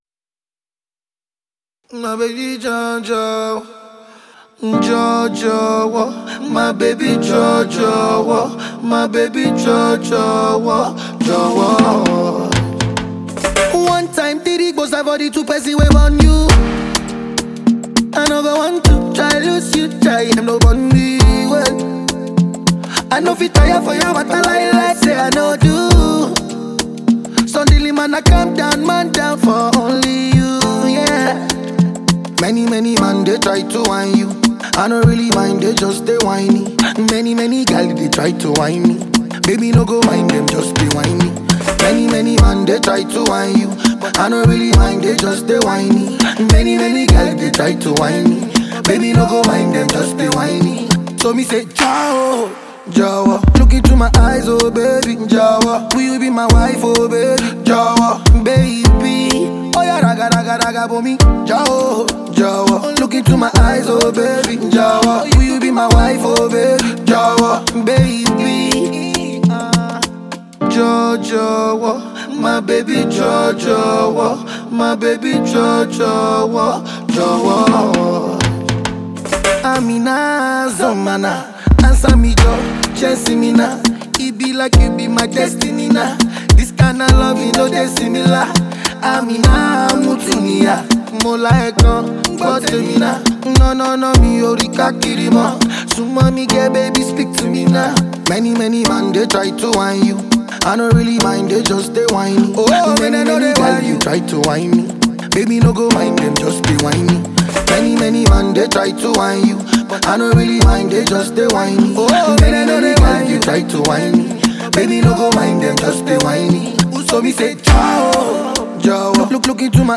The album mixes Afrobeats with pop and hip-hop sounds.
fun, lively, and full of good vibes